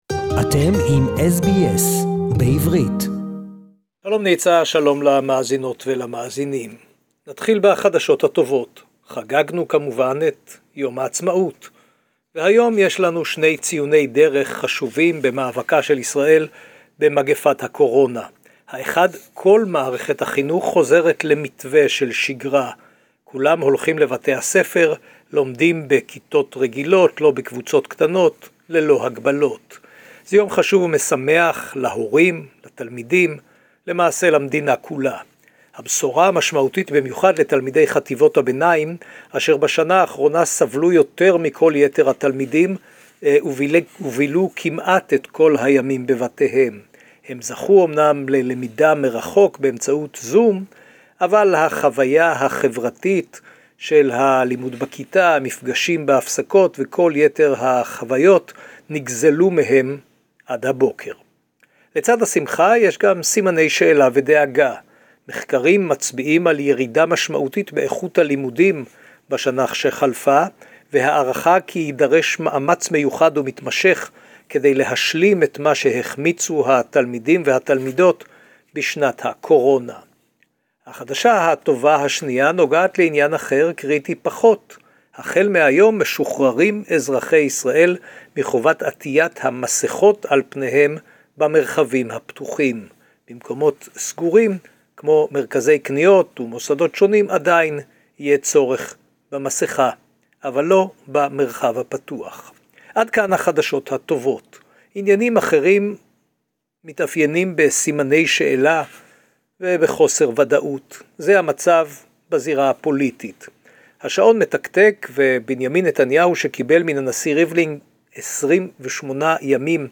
Iran-Israel tensions since Natanz incident, SBS Jerusalem report